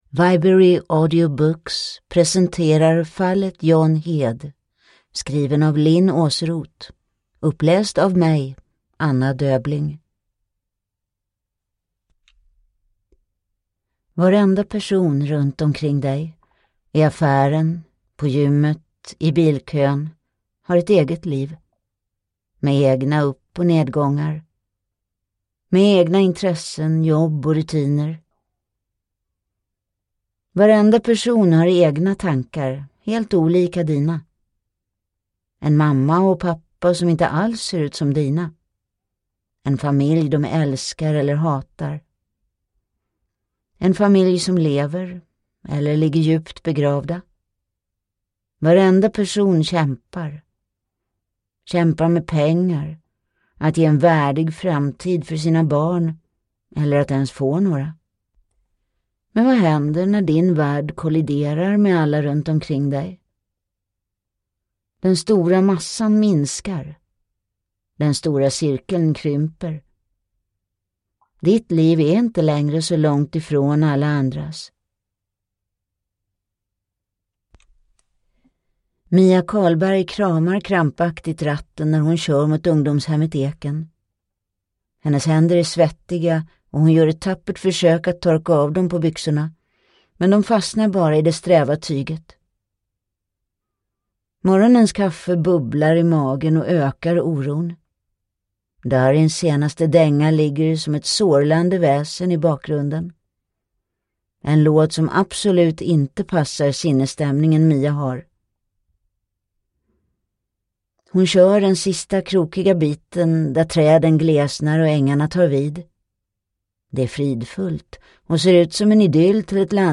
Fallet John Heed (ljudbok) av Linn Åsroth